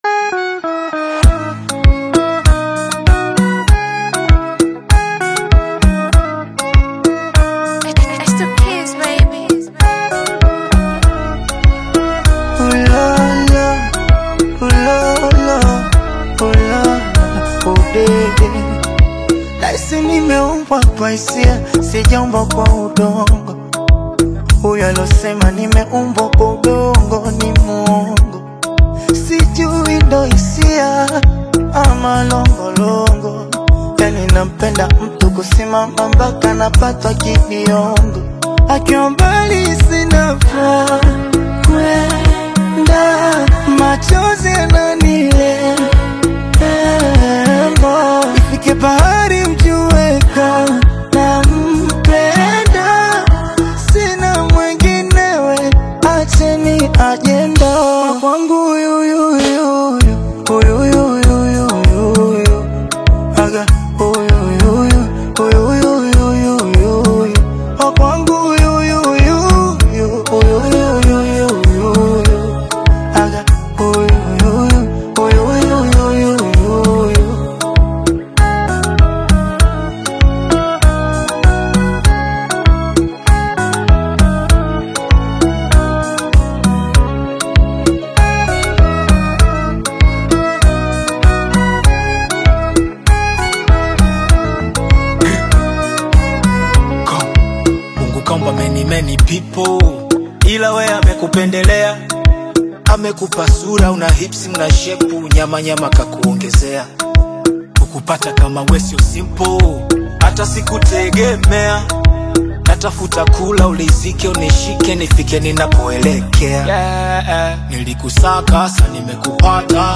dynamic Afro-beat/hip-hop single